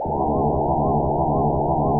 Sine Wave Modulated Flanger.wav